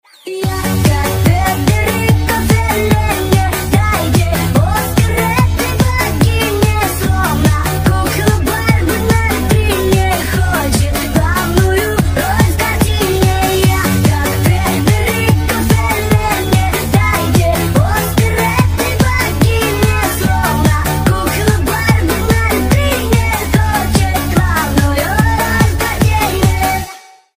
мужской голос
веселые
быстрые
ремиксы